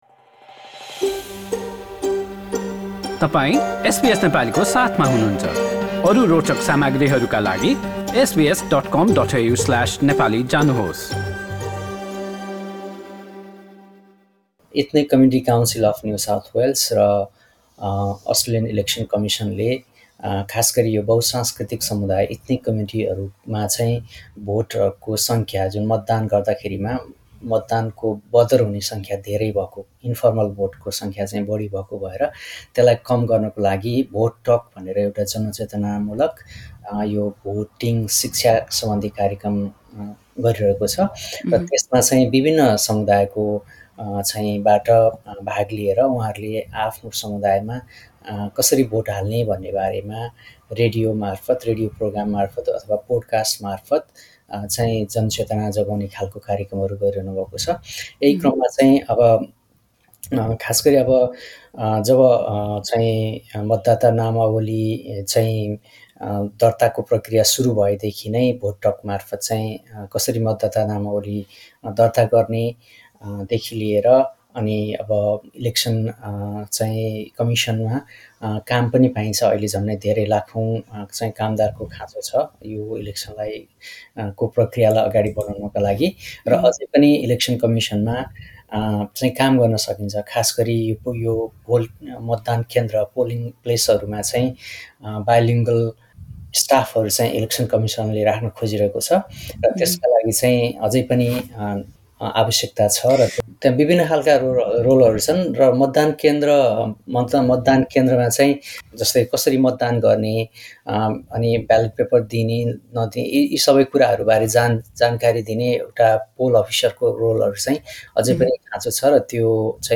पुरा कुराकानी